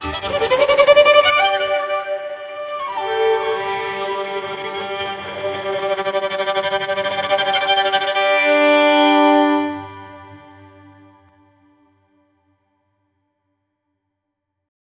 alien-invasion-with-violi-klwmhdcl.wav